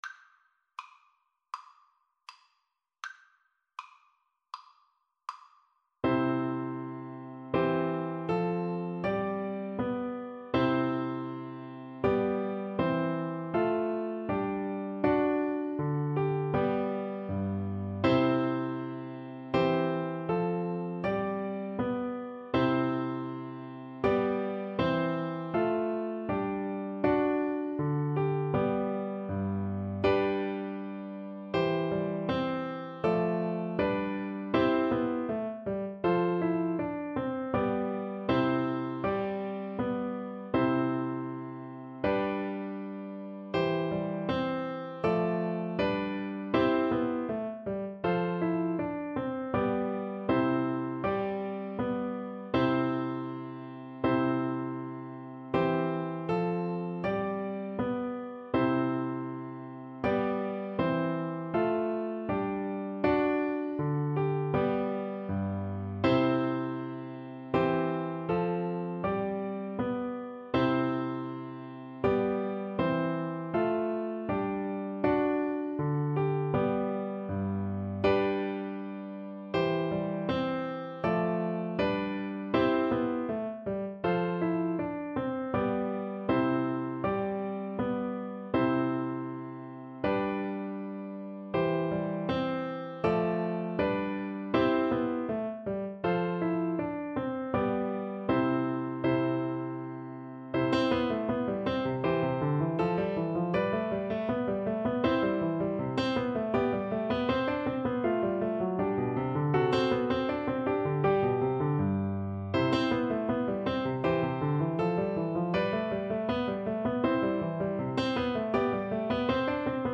• Unlimited playalong tracks
Allegretto =80
Classical (View more Classical Recorder Music)